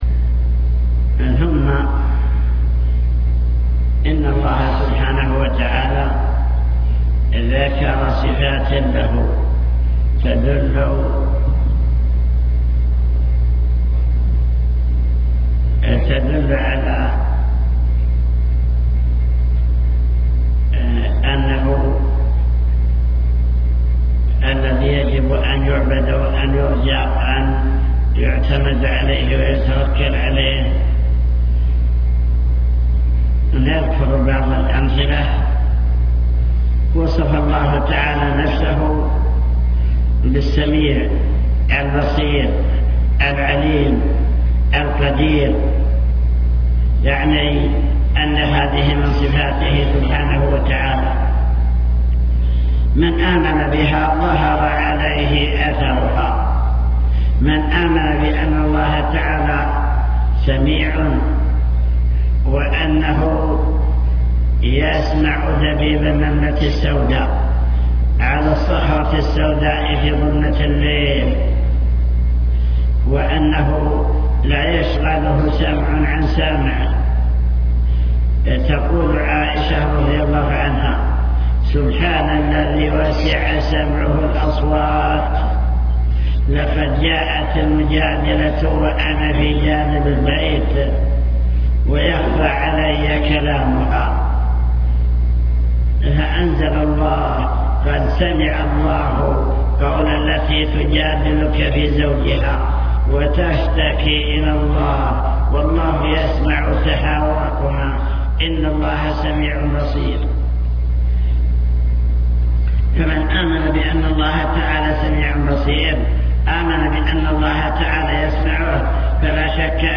المكتبة الصوتية  تسجيلات - محاضرات ودروس  محاضرة الإيمان باليوم الآخر